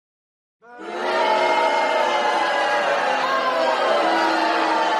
Crowd Booing
Crowd Booing is a free sfx sound effect available for download in MP3 format.
088_crowd_booing.mp3